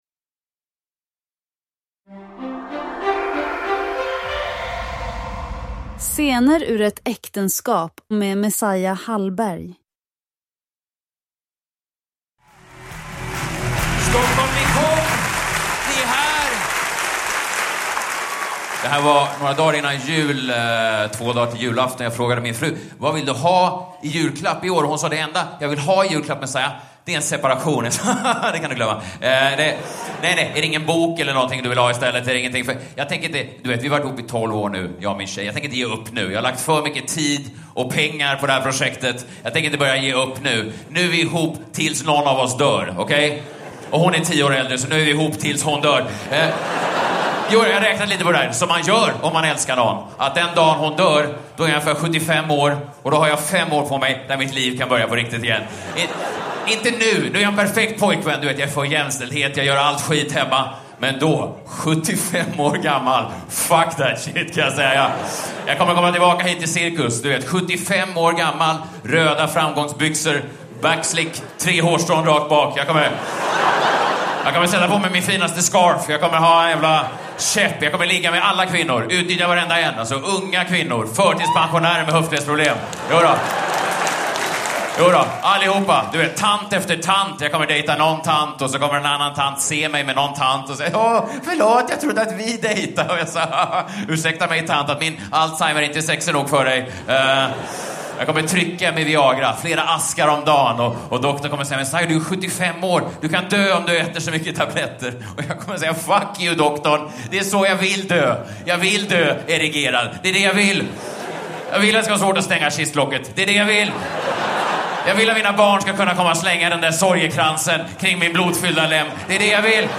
Uppläsare: Messiah Hallberg
Ljudbok